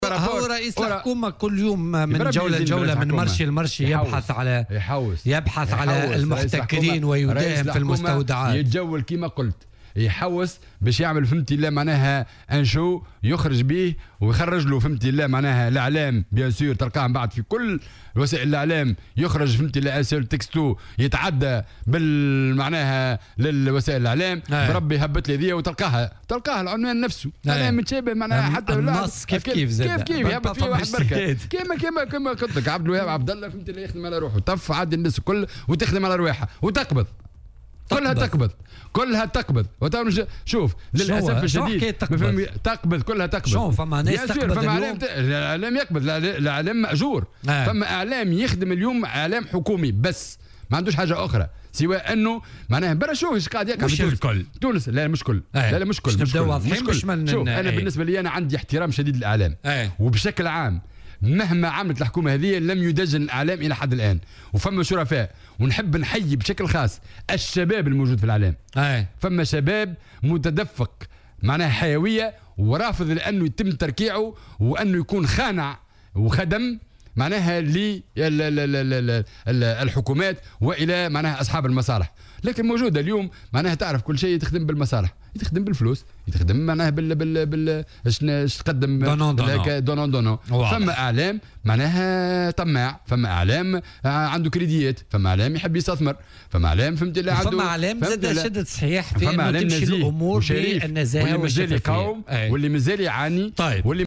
وأضاف الرحوي في مداخلة له اليوم في برنامج "بوليتيكا" على "الجوهرة أف أم" أن الشاهد يسعى لتلميع صورته لا غير، واصفا بعض وسائل الإعلام بالإعلام "المأجور"، وفق تعبيره.